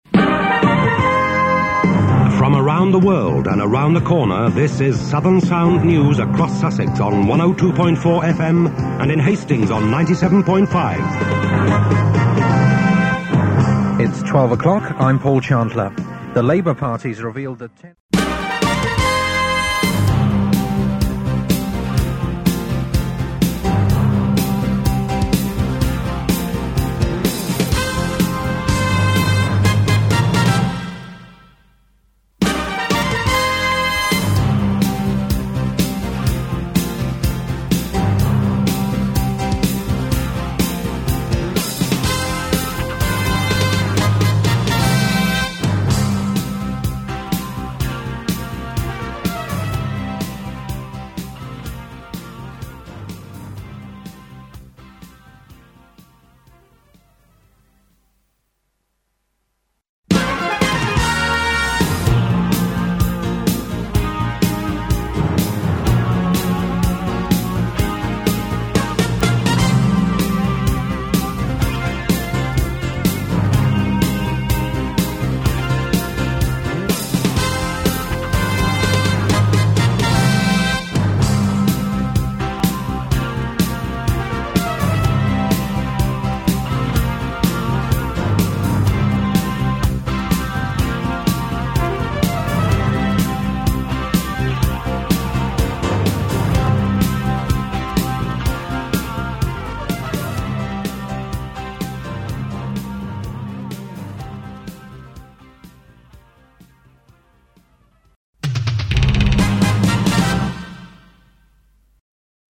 News jingles